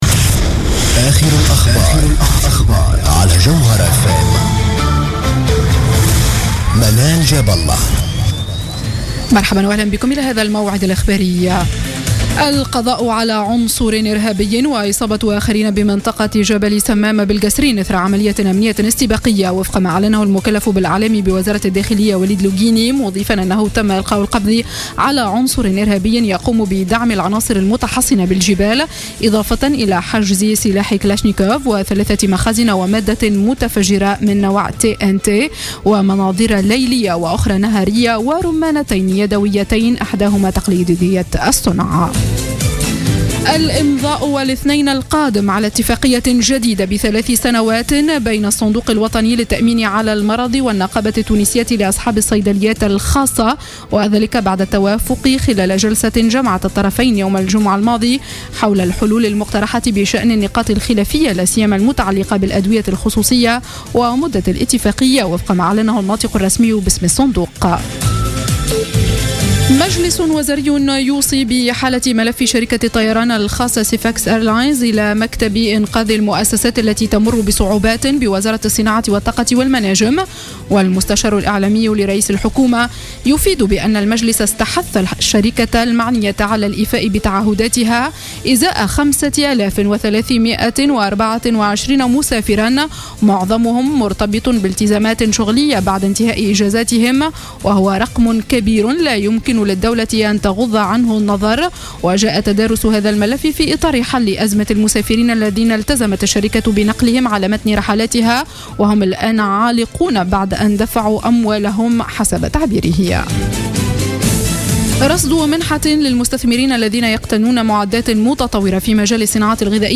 نشرة أخبار السابعة مساء ليوم الاثنين 10 أوت 2015